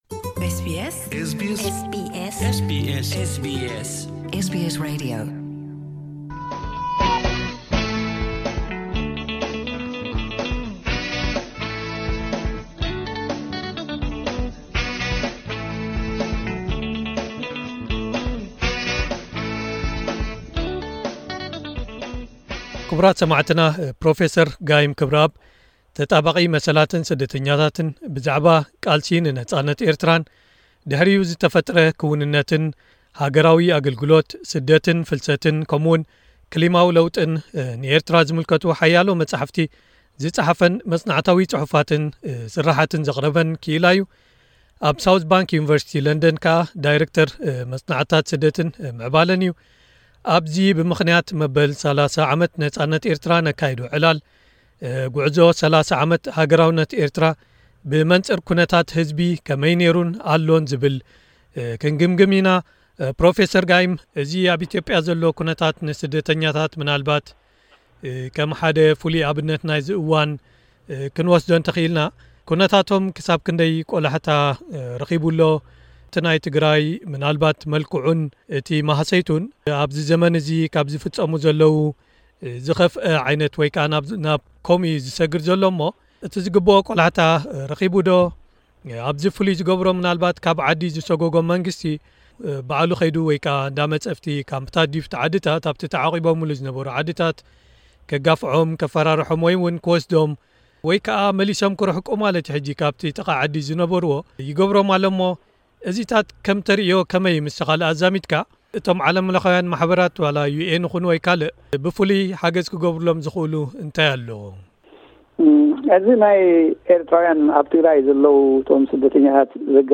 ቃለመሕትት ኤስቢኤስ ትግርኛ